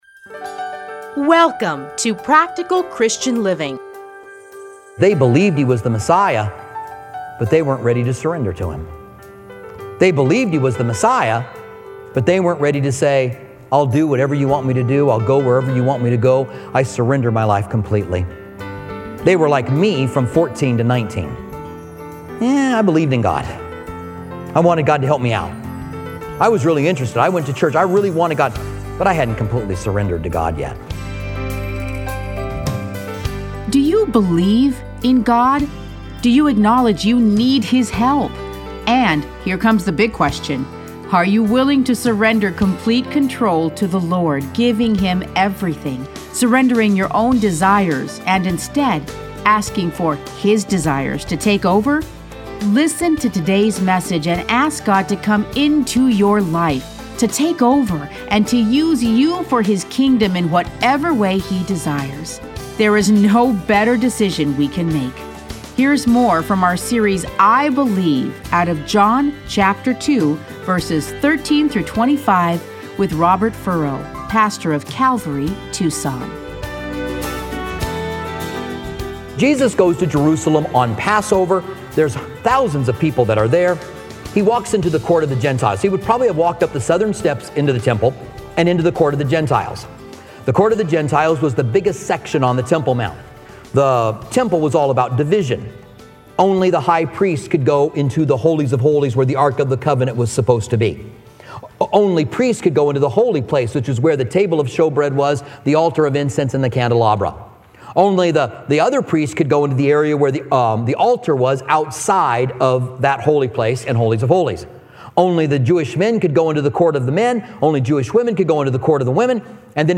Listen to a teaching from John 2:13-25.